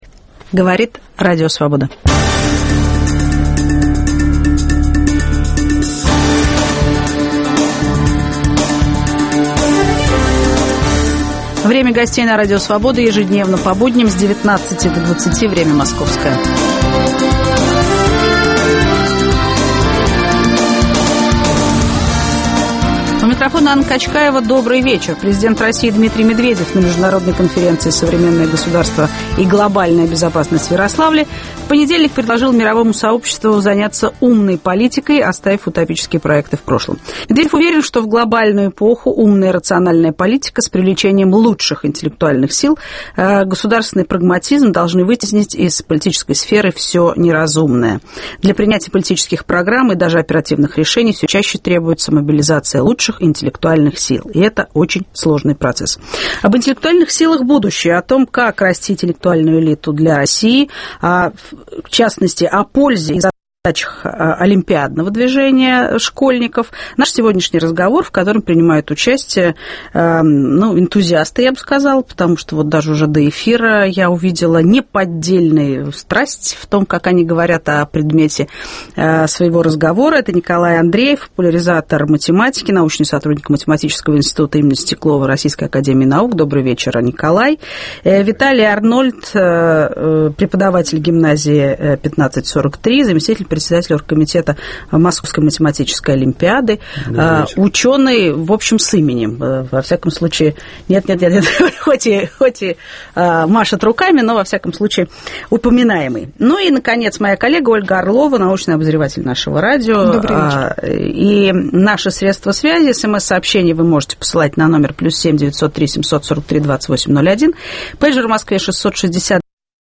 В прямом эфире программы - впечатления участников международной конференции из Ярославля. Основная тема программы: интеллектуальный потенциал нации и олимпиадное движение.